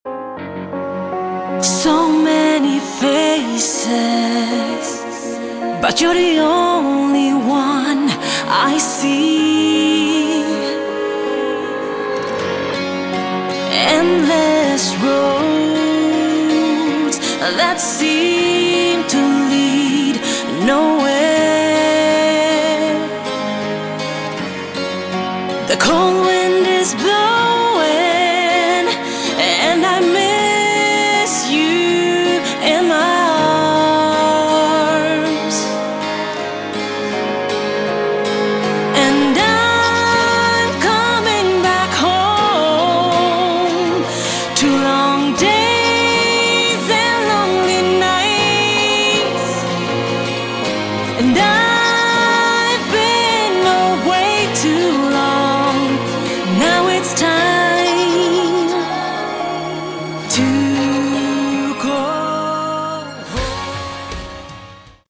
Category: Melodic Rock
lead vocals
guitars, bass
acoustic guitars
keyboards, backing vocals, drums